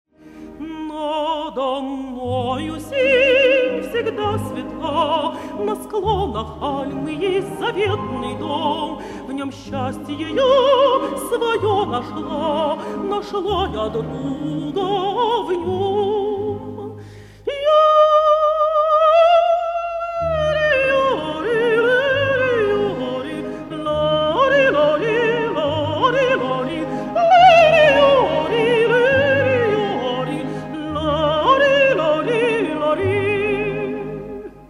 На 11-м диске есть две записи с применением «йодль»: «На склонах Альмы» и «Тирольское танго».
концертный ансамбль